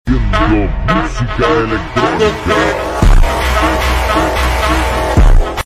Fyp Viral Foryoupage Fypシ Car Sound Effects Free Download